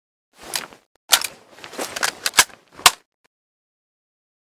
ppsh41_reload.ogg